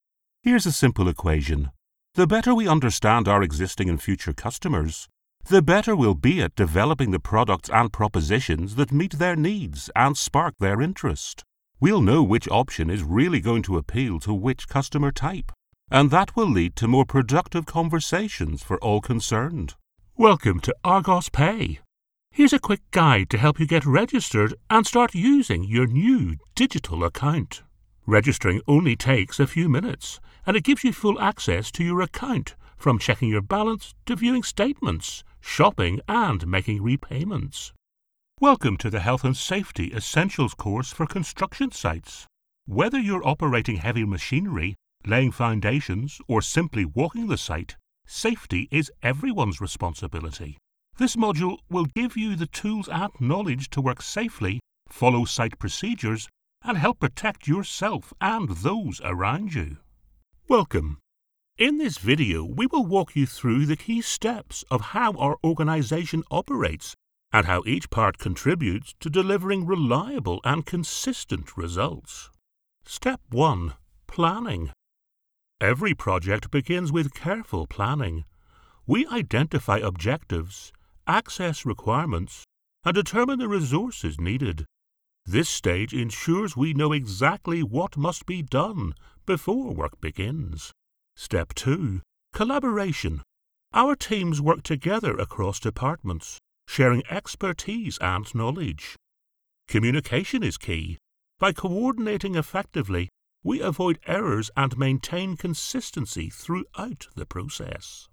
A Northern Irish Voice
Learning Reel
I have a Northern Irish Accent which can be street or upperclass.